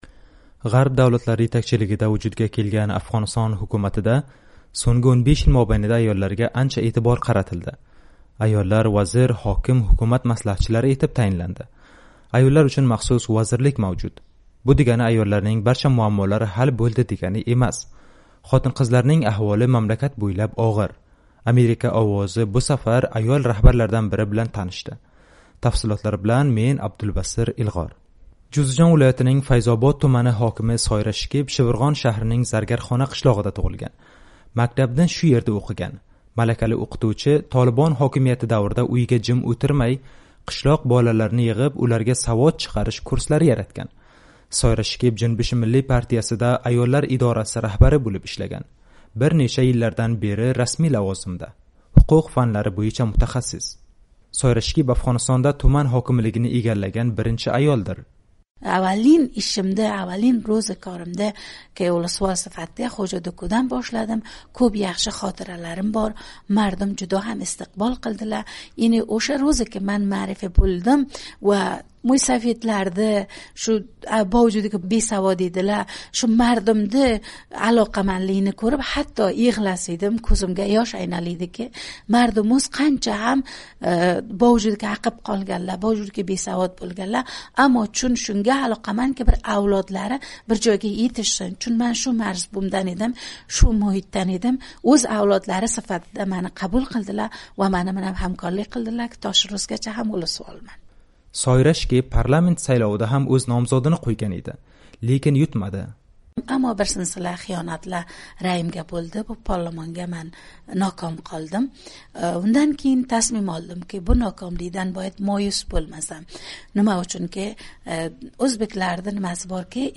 Afg'oniston: O'zbek siyosatchi Soyra Shikib bilan suhbat